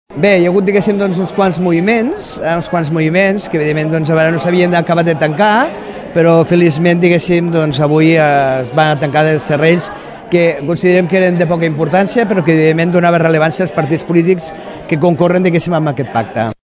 El president del Consell Comarcal del Maresme, Miquel Àngel Martínez, celebrava que s’hagin superat les diferències per a l’acord entre ERC, CiU i PSC.